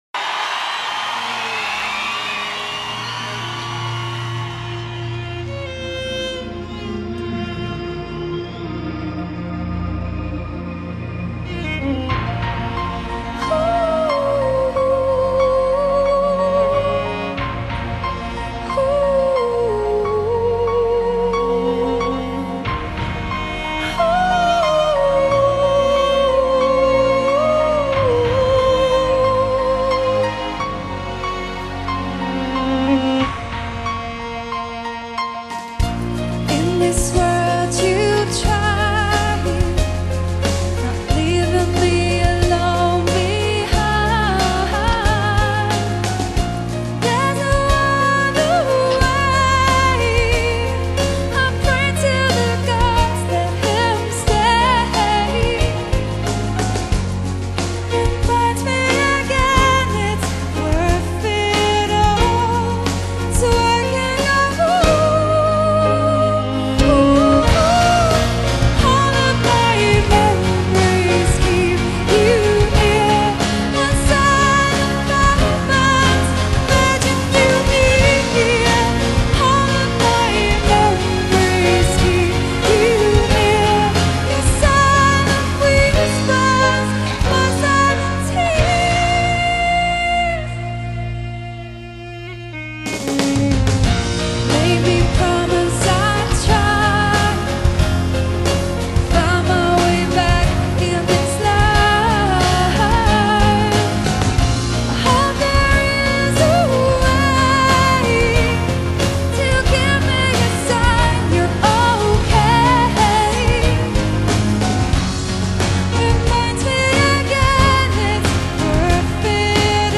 (Live) Symphonic-Gothic Rock